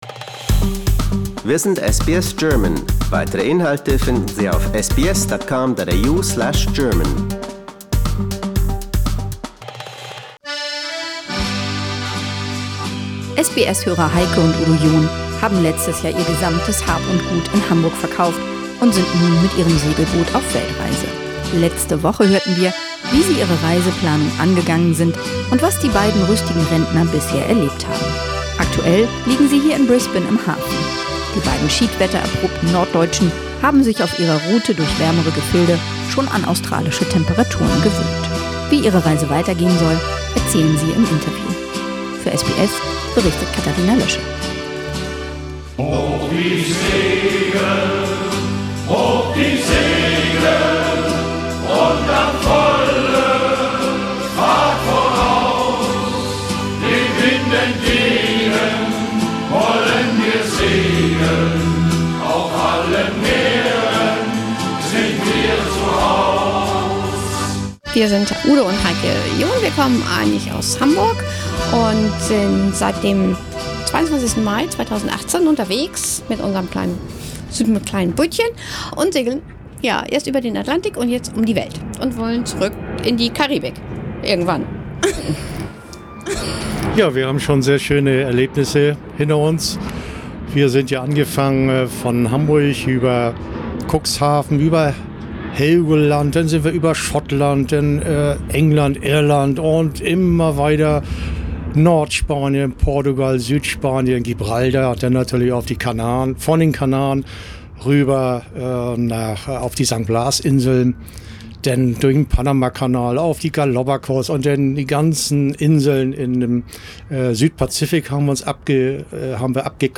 In an interview with SBS correspondent